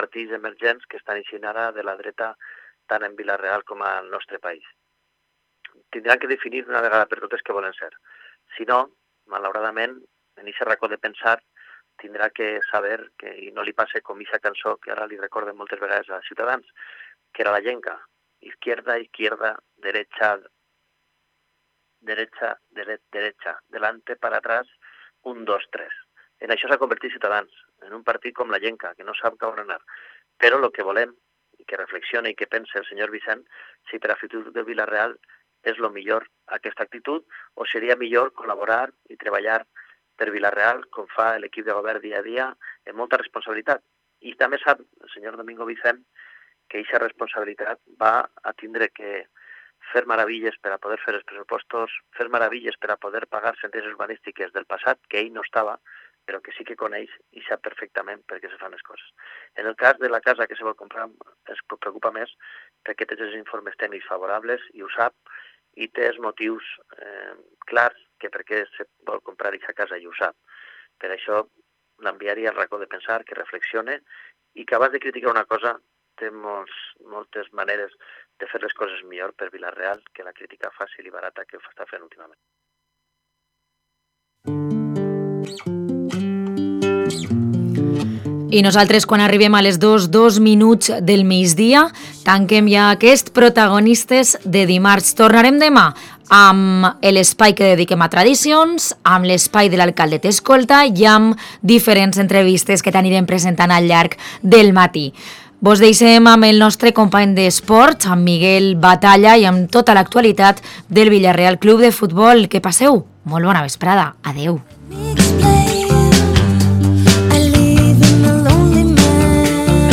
desde el aeropuerto de Castellón, camino a San Petersburgo, donde nuestro Villarreal C.F. juega eliminatoria europea, nos cuenta las últimas noticias y novedades del equipo.